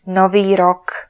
Slovak voice announciation
novy_rok.wav